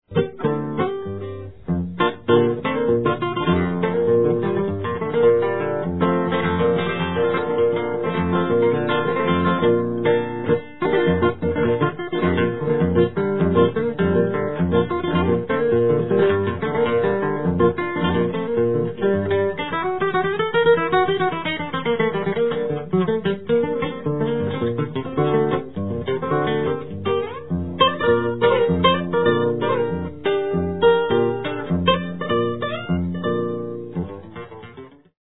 Guitar - 2:45